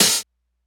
OPENHAT (LIVE).wav